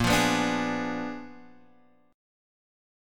A#7b9 chord